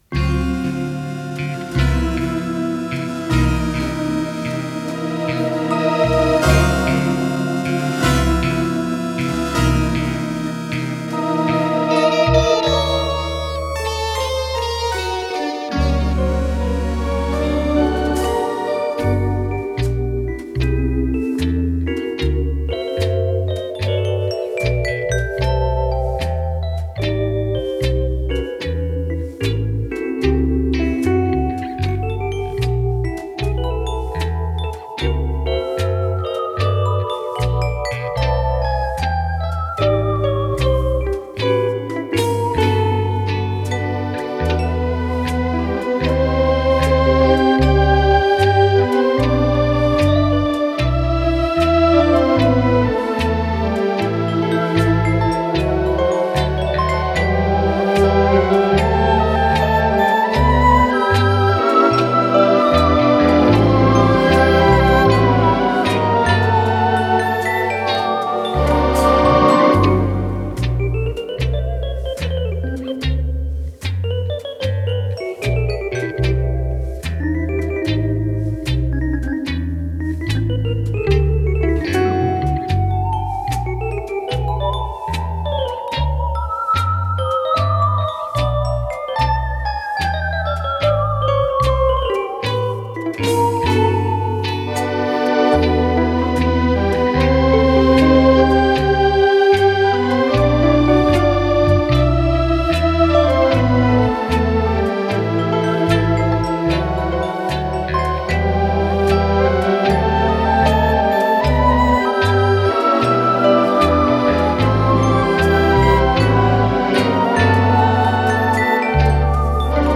ВариантДубль моно